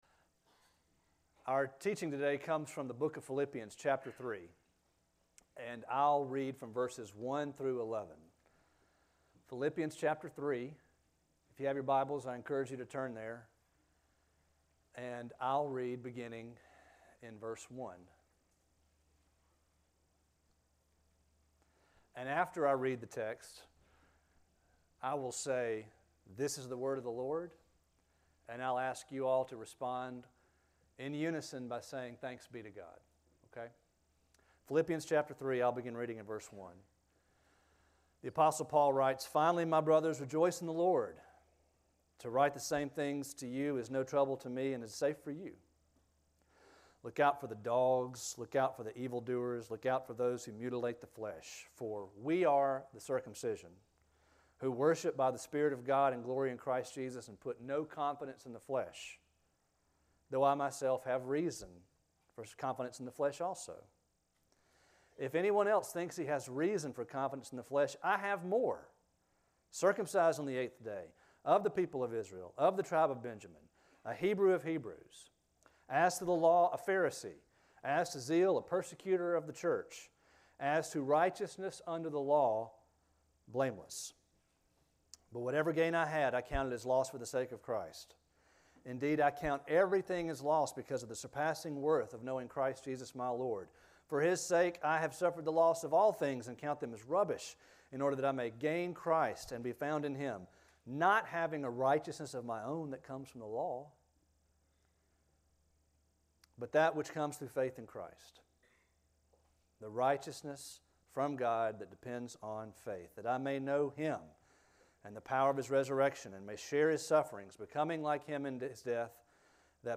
A sermon from the series "Have this Mind in You."